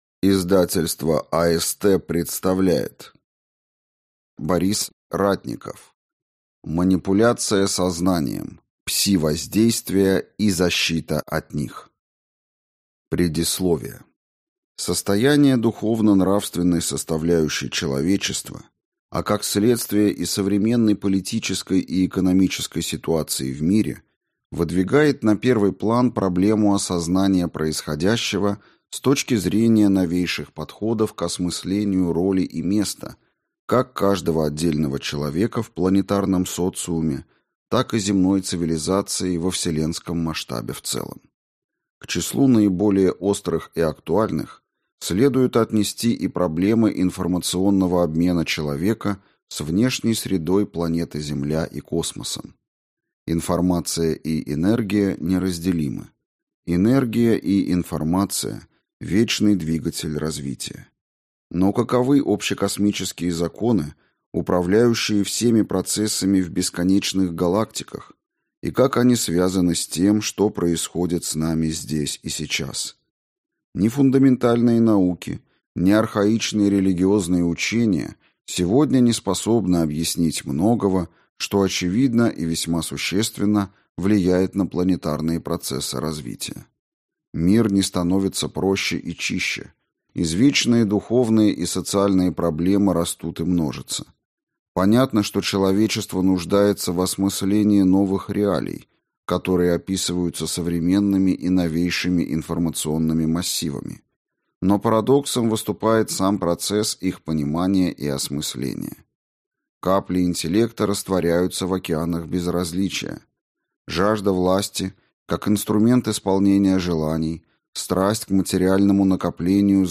Аудиокнига Манипуляция сознанием. Пси-воздействия и защита от них | Библиотека аудиокниг